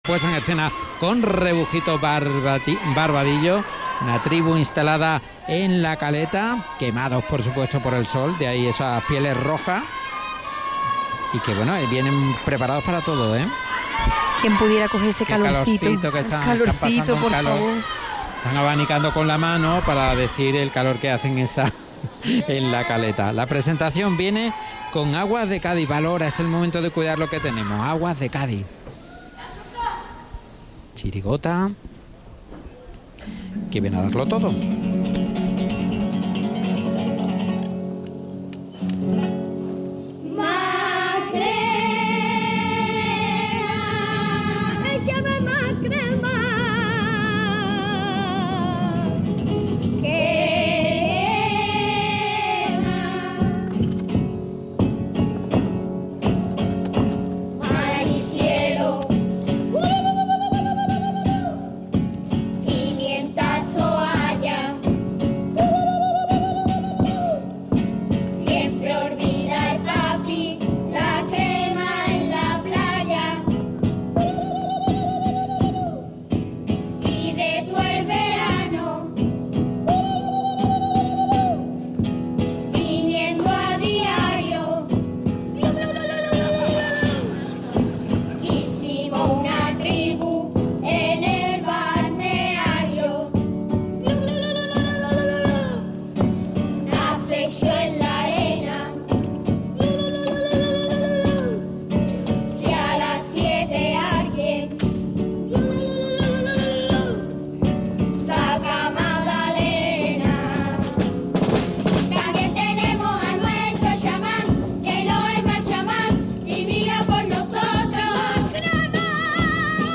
Audio de La cantera del Carnaval de Cádiz en Podium Podcast
Chirigota Infantil - Los pieles rojas de la caleta Final